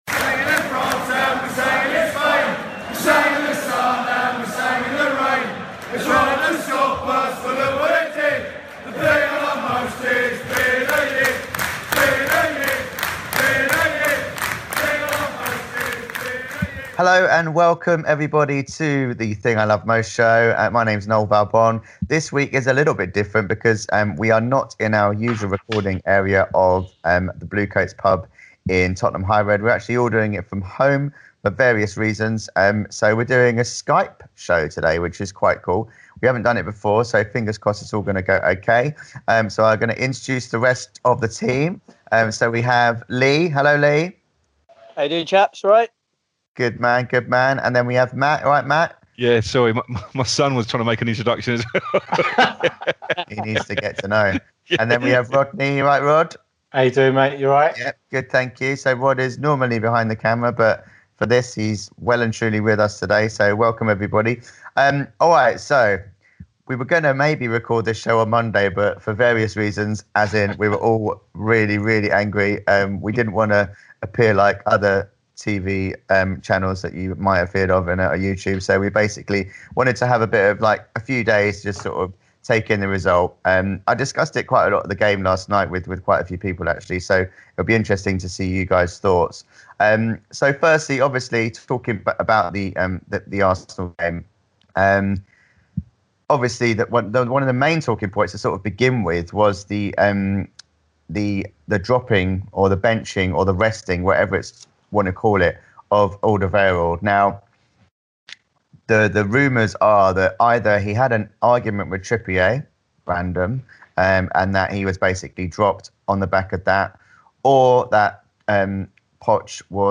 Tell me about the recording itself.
In this week's show, we have a Skype special